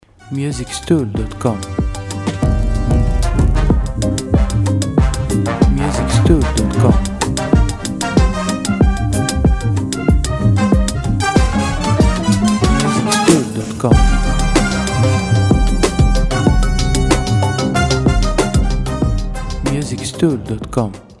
• Type : Instrumental
• Bpm : Allegro
• Genre : Polka Style / Jazz Music / Rap Music